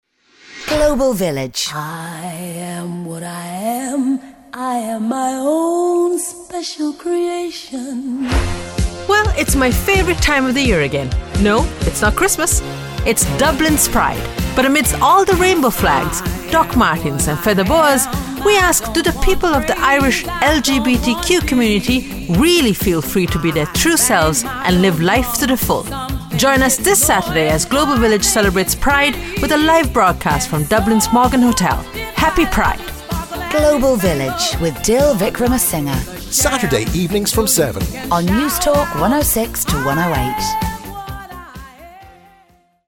The ground-breaking and award winning weekly social justice and mental health radio programme will air live from The Morgan Hotel.